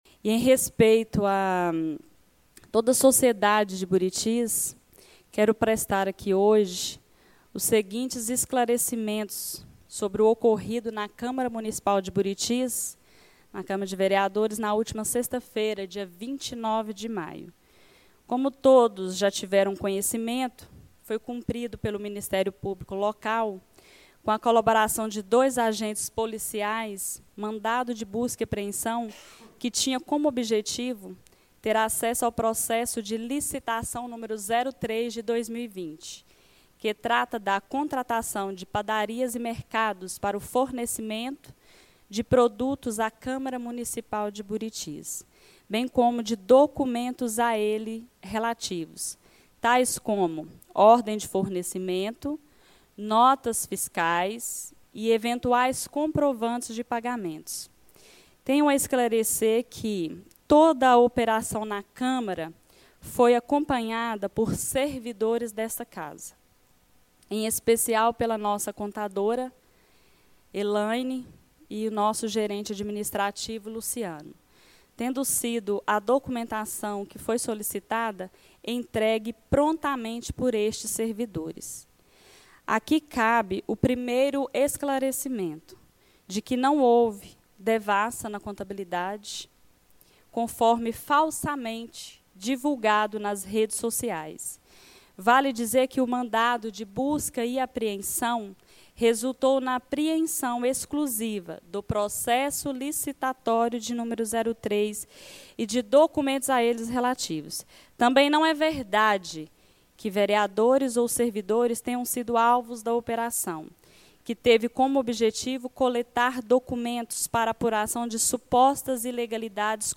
A Presidente da Câmara Municipal de Buritis, a vereadora Wânia Souza, fez uma nota de esclarecimento à população durante a Reunião Ordinária da Câmara Municipal de Buritis da última segunda-feira (1), a nota se refere ao mandado de busca e apreensão cumprido na última sexta-feira (29) na sede do Poder Legislativo do município.